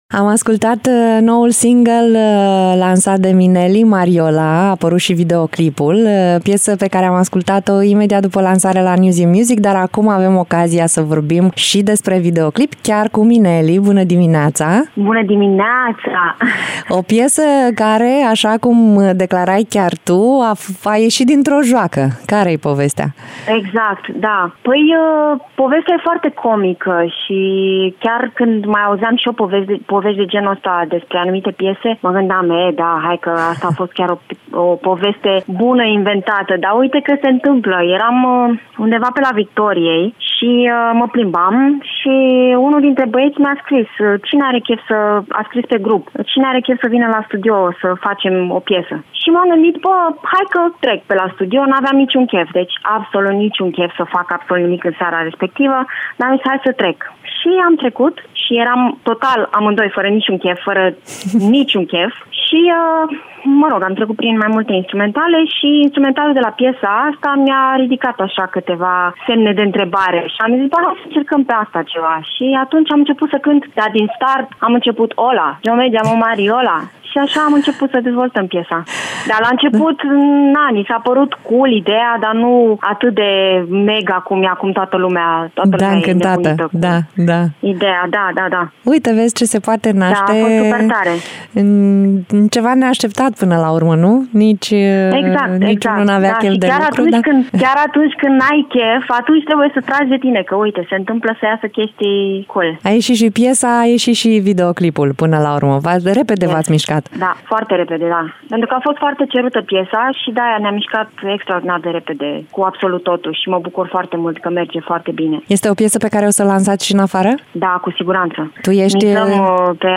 Minelli în direct la Radio Iaşi.
16-Mart-Interviu-Minelli.mp3